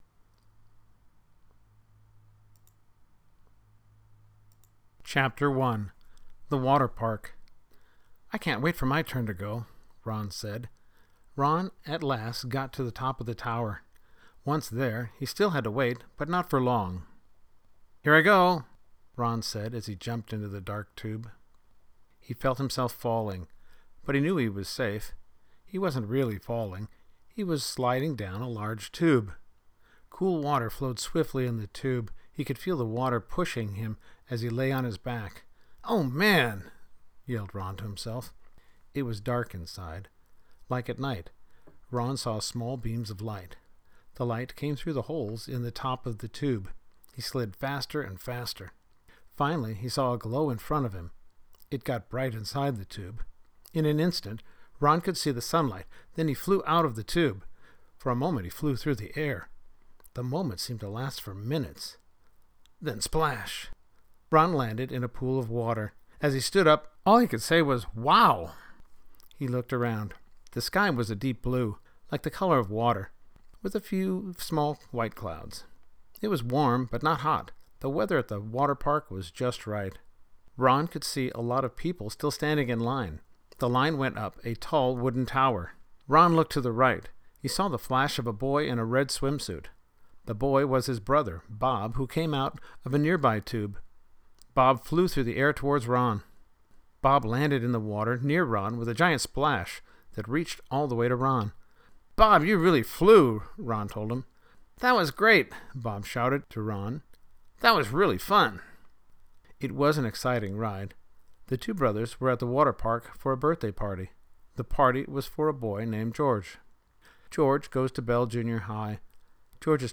ISBN: N/A (audiobook) English Edition $0.99